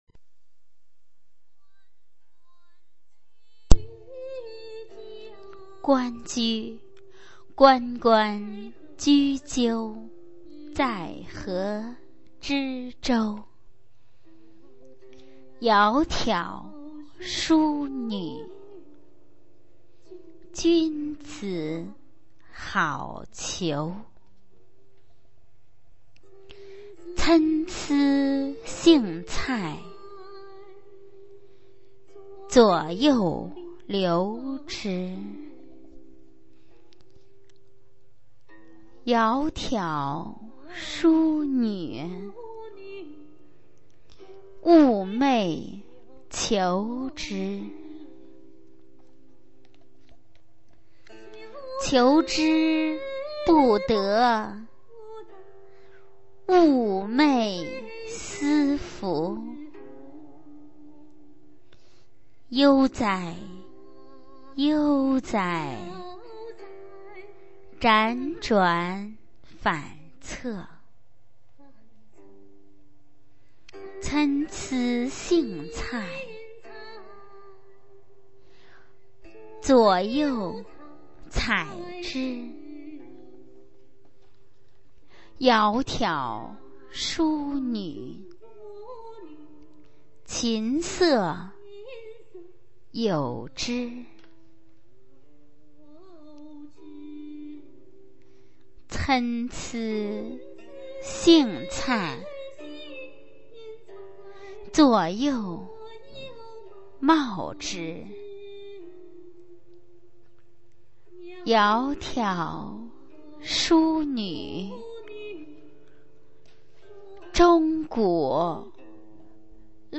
《关雎》原文与译文（含赏析及朗读）
语文教材文言诗文翻译与朗诵 初中语文九年级上册 目录